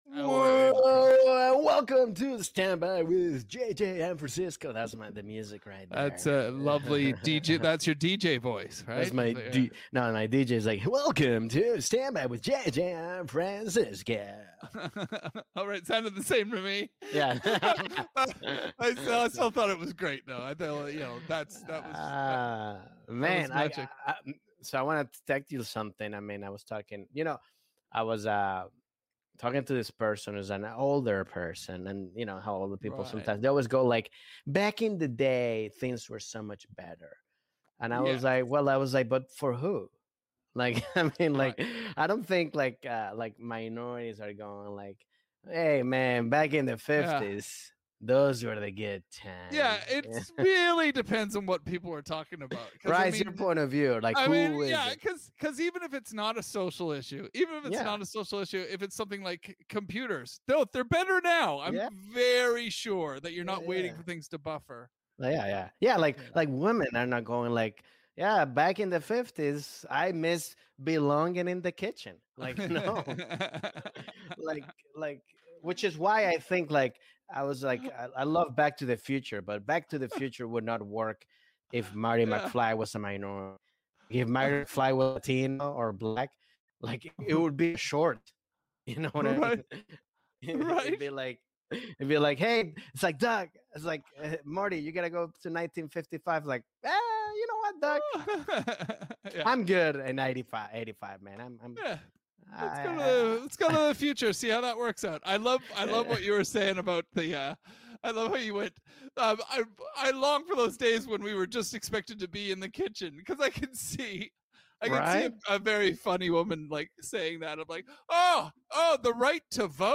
This week, one of the forefathers of Chilean stand up comedy, and all around hilarious guy FABRIZIO COPANO stops by to chat with the guys!